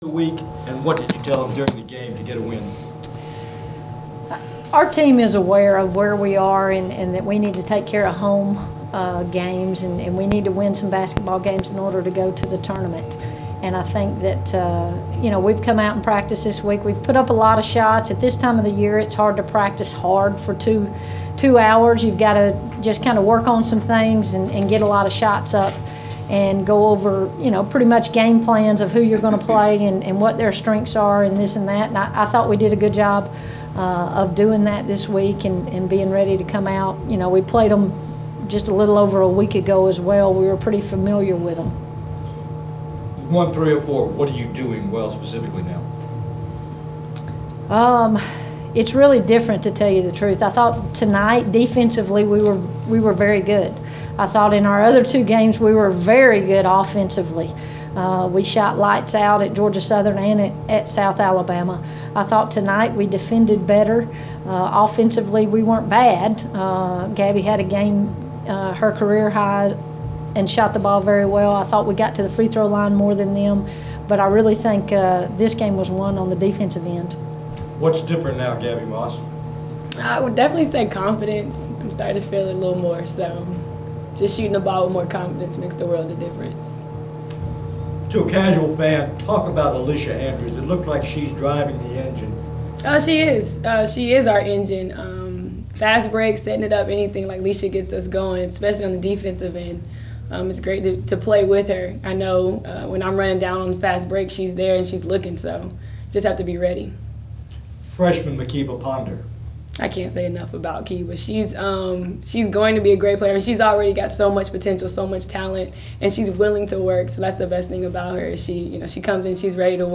gsu-wbb-post-south-alabama.wav